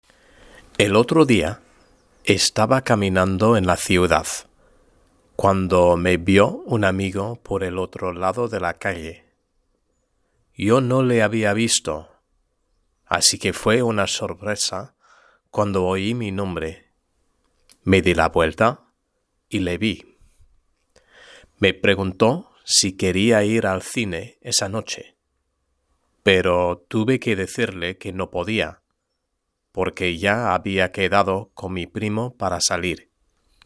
Lectura en voz alta: 2.1 El tiempo libre (H)